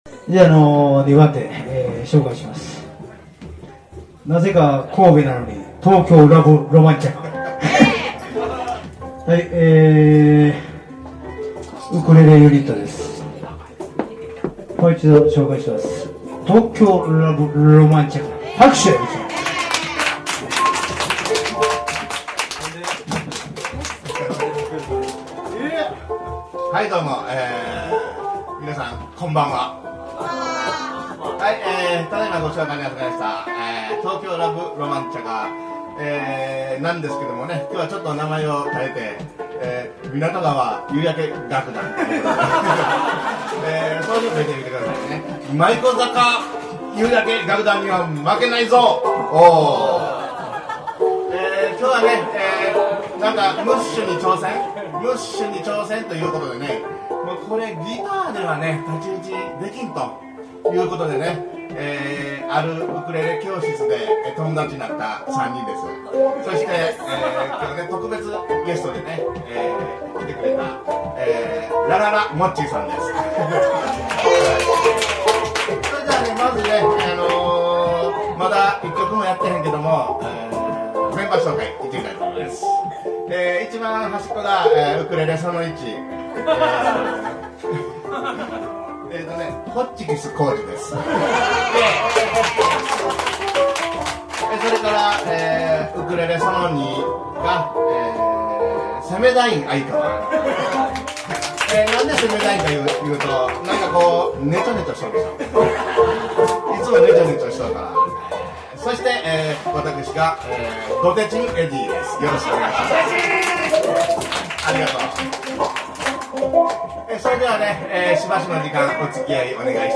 �G�h�}��'s��y�� Ukulele Live �` HAPPY LAULA 08.10.26 �`